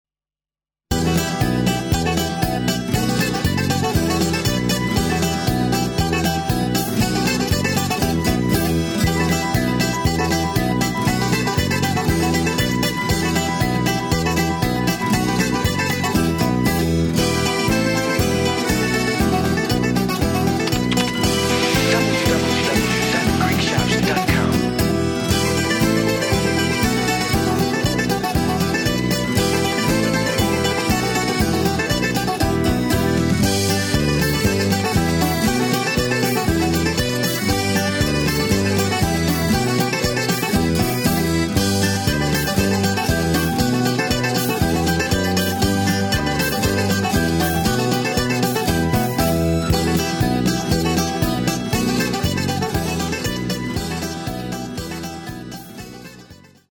נעימות בוזוקי סירטאקי